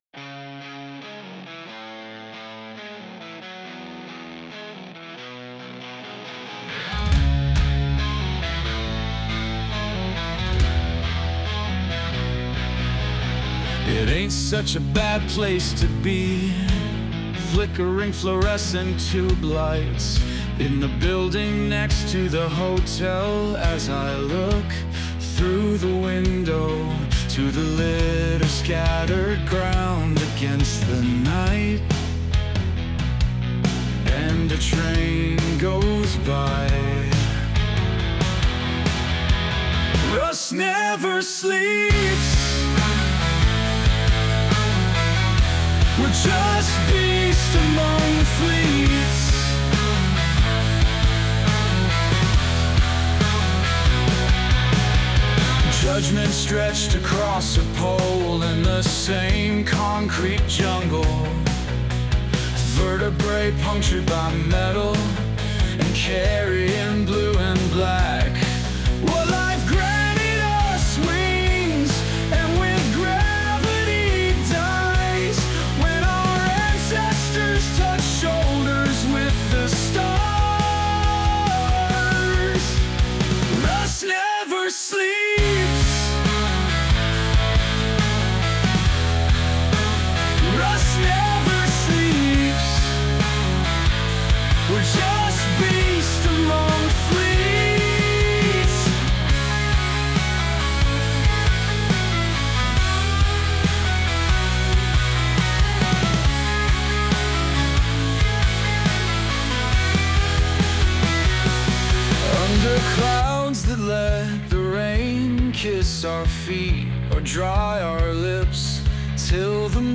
• Rust Never Sleeps（グランジ）： グランジというジャンル指定で生成を試みましたが、何度か試行錯誤した結果、最終的に満足のいく楽曲が完成しました。当初はカントリー調の楽曲が生成される傾向がありましたが、プロンプトをより具体的に設定することで、狙い通りのグランジサウンドを実現できました。